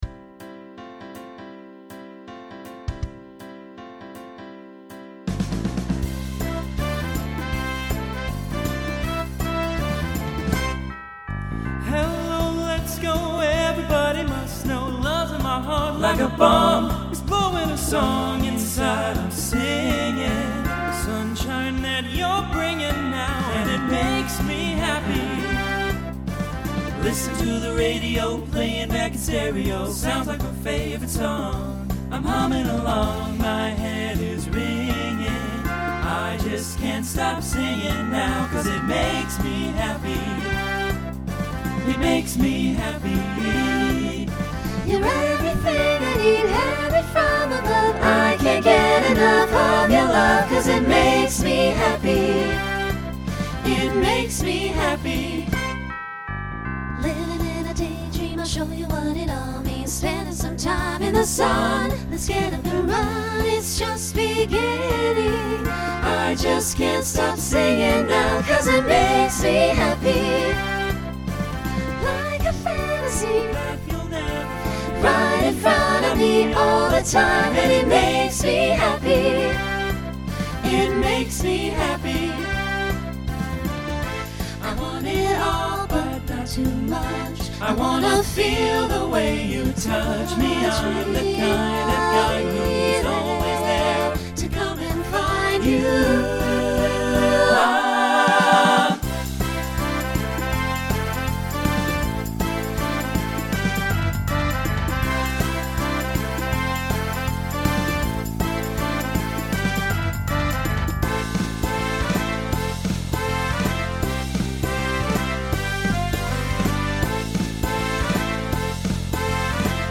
Genre Swing/Jazz Instrumental combo
Voicing SATB